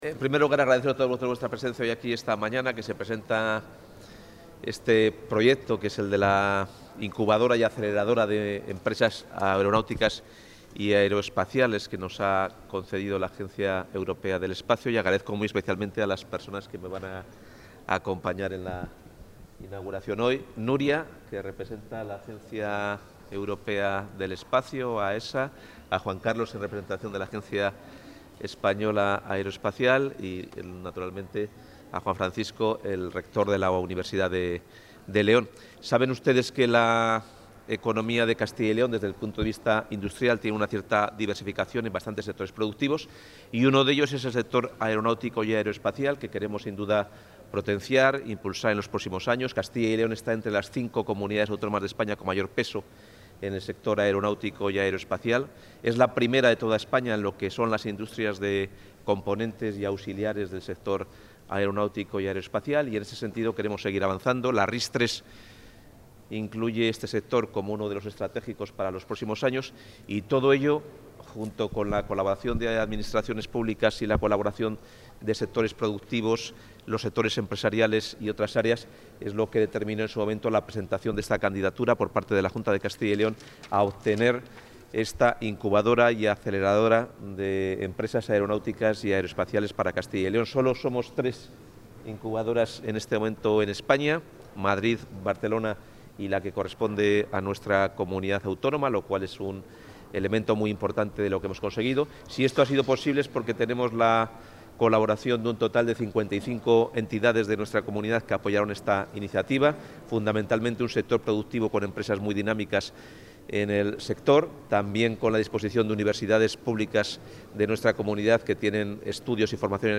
El consejero de Economía y Hacienda, Carlos Fernández Carriedo, ha inaugurado, en el Parque Tecnológico de León, la...
Intervención del consejero.